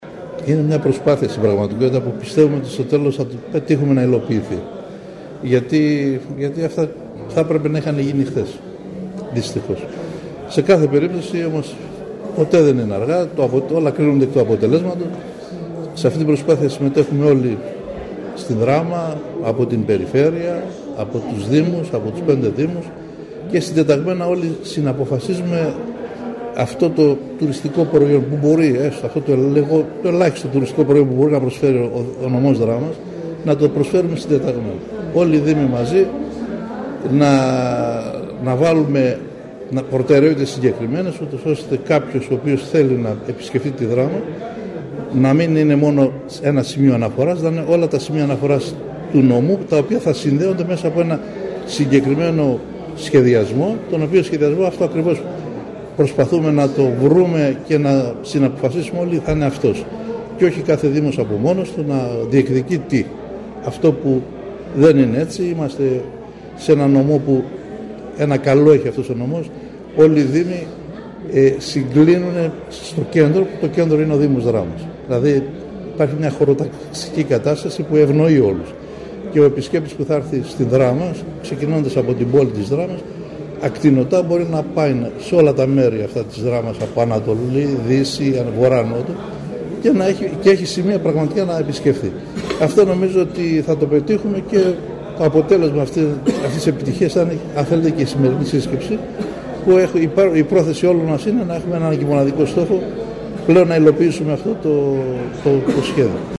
Πραγματοποιήθηκε το απόγευμα της Πέμπτης 4 Απριλίου στο ξενοδοχείο Κούρος της Δράμας, η εκδήλωση κατά την οποία ανακοινώθηκε επισήμως, η έναρξη λειτουργίας του συνεργατικού σχήματος που απαρτίζεται  από την Ένωση Ξενοδόχων Δράμας και τους 5 Δήμους του νομού, με  σκοπό  την μελέτη, καταγραφή του τουριστικού προϊόντος της Περιφερειακής Ενότητας Δράμας καθώς και την δημιουργία ενός DMMO’s οργανισμού διαχείρισης προορισμού.
Αθανασιάδης Θόδωρος – Δήμαρχος Προσοτσάνης